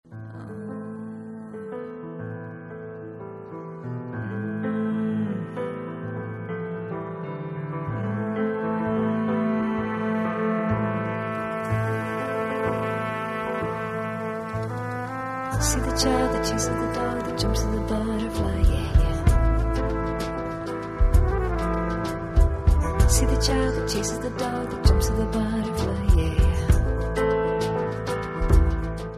(Okay, that's just music critic speak for "country twang.")